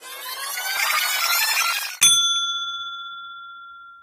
rewinder.ogg